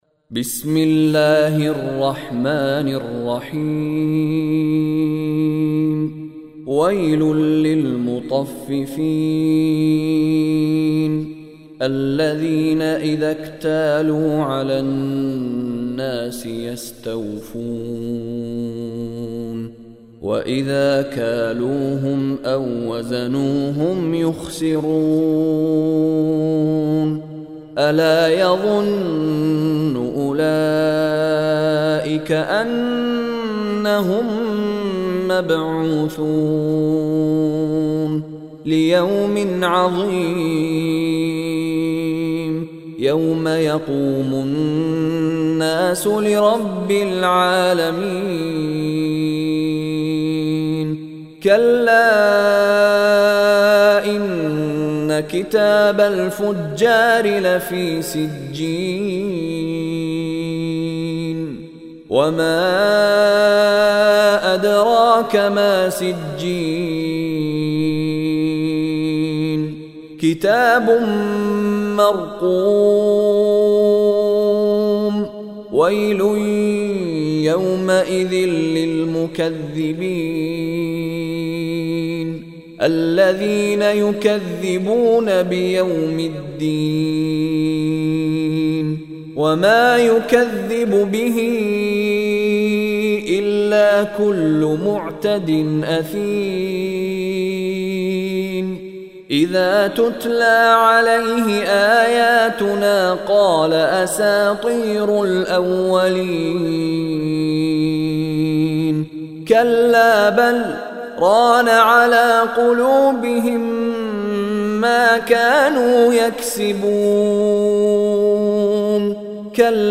Surah Mutaffifin Recitation by Mishary Rashid
Surah Mutaffifin listen online mp3 recited in Arabic in the beautiful voice of Sheikh Mishary Rashid Alafasy.